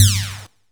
laserLarge_003.ogg